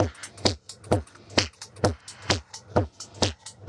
Index of /musicradar/uk-garage-samples/130bpm Lines n Loops/Beats
GA_BeatErevrev130-02.wav